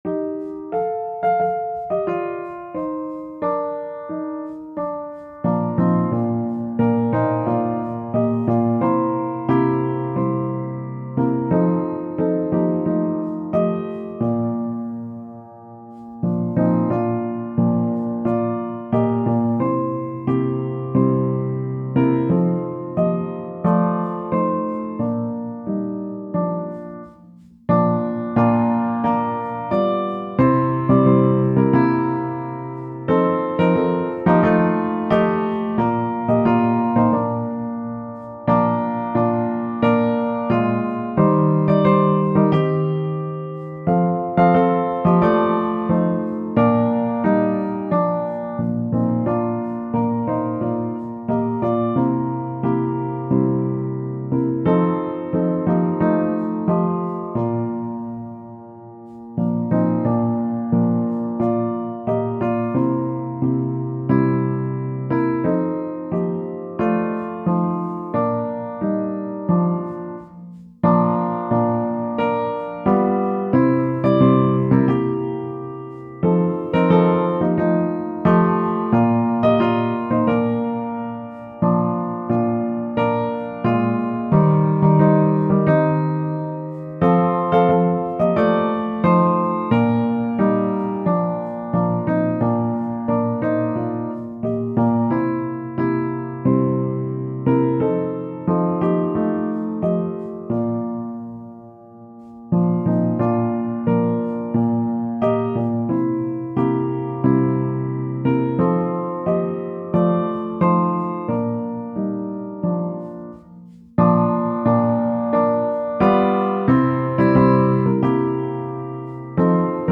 Tempo: Normaal